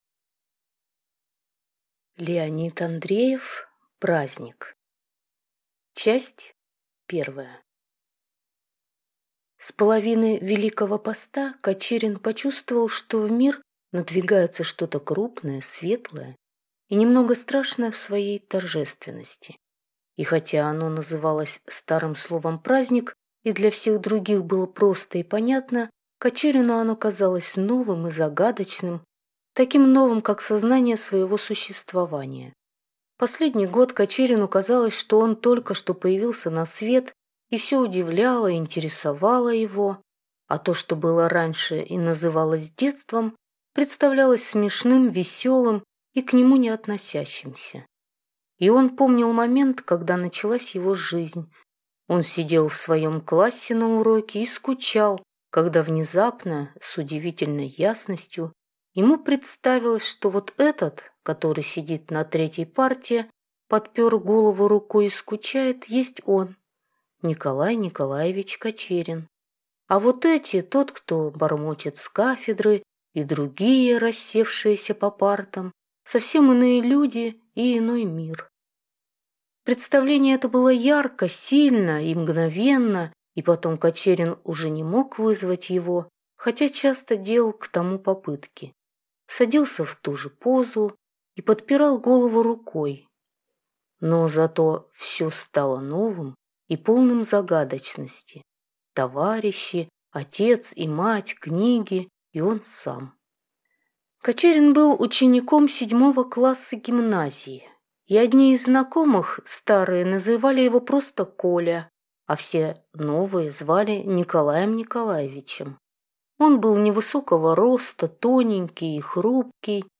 Аудиокнига Праздник | Библиотека аудиокниг